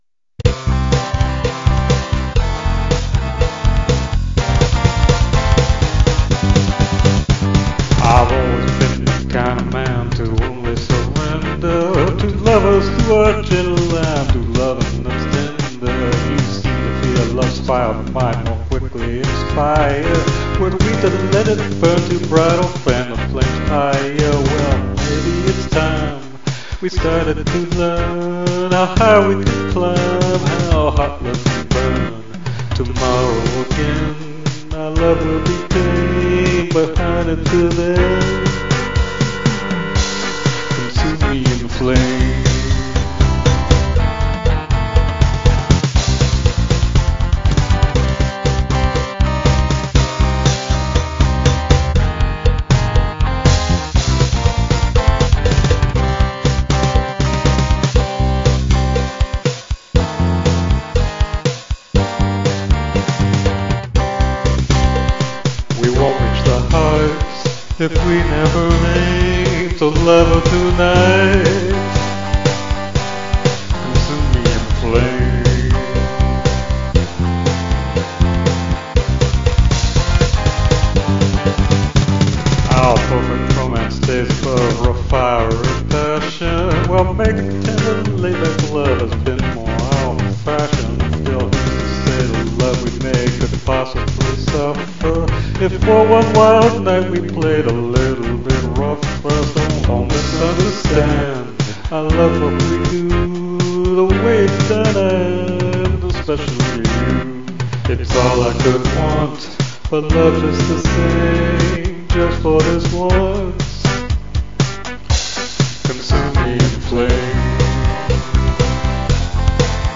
deliberate 4/4 blues, female or male voice